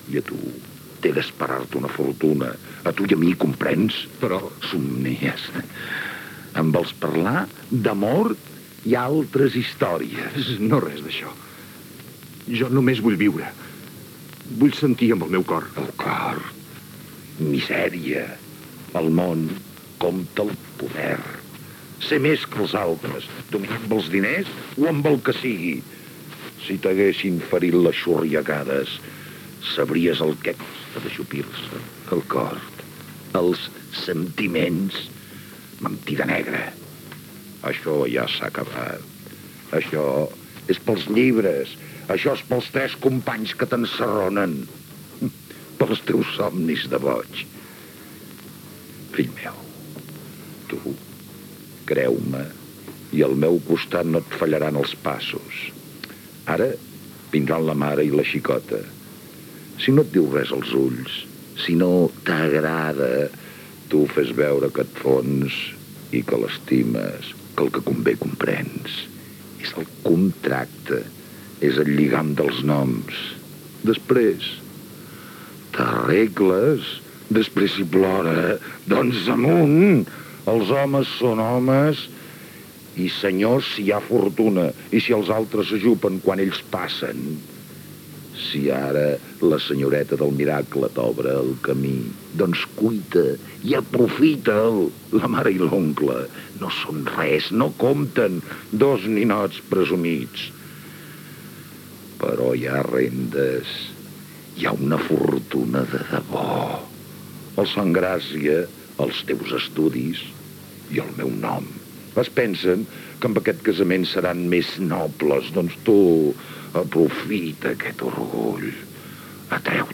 Fragment de la versió radiofònica de l’obra de Josep Maria de Segarra «La Corona d’Espines»
Ficció